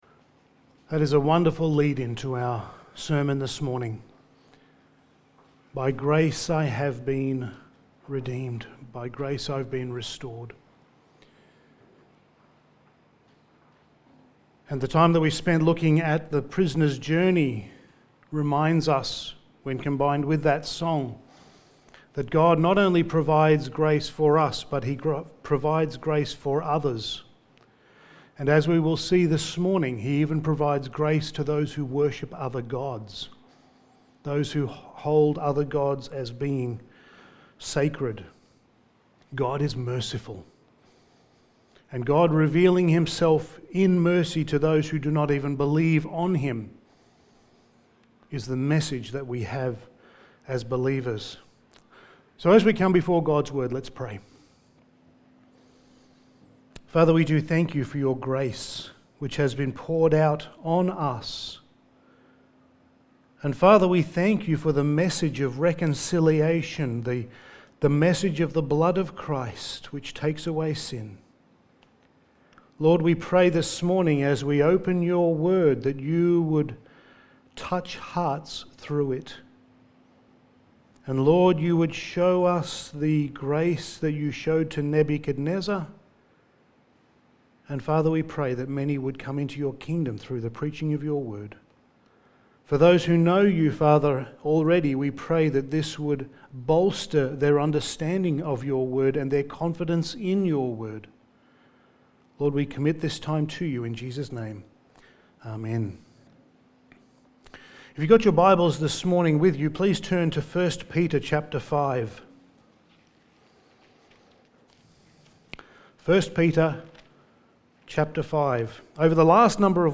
Passage: Daniel 4:1-27 Service Type: Sunday Morning